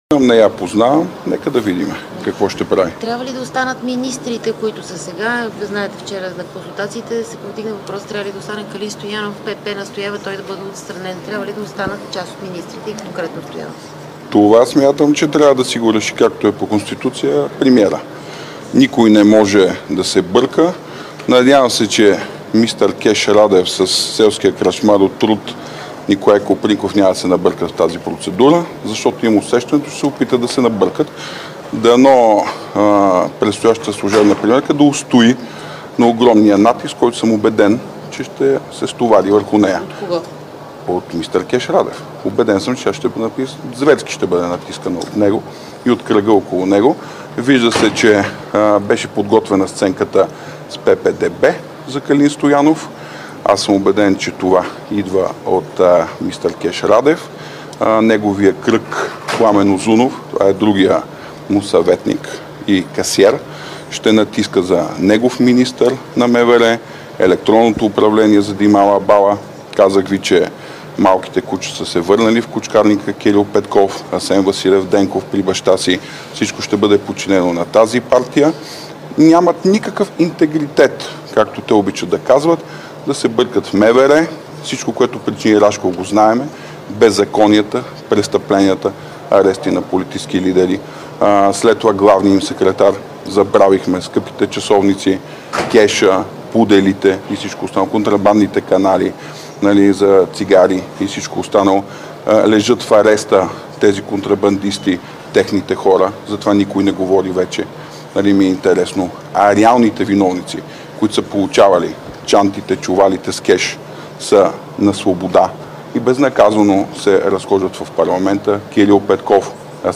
9.15 - Брифинг на лидера на ГЕРБ Бойко Борисов за новия служебен премиер.
Директно от мястото на събитието